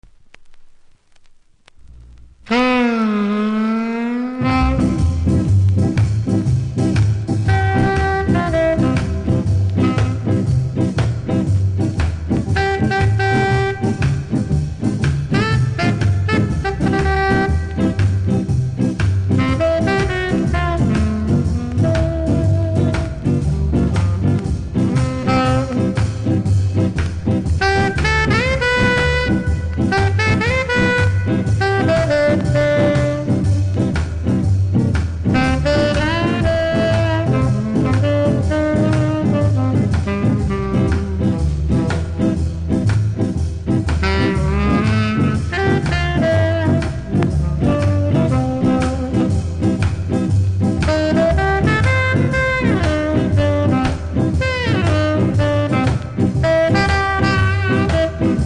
JAMAICAN 〜60'S
CONDITION：VG++ ( ノイズ数発あり）